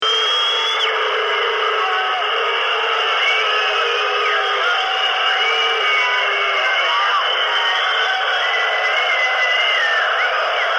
Concert de sifflets 169 ko MP3
fluitconcert[1].mp3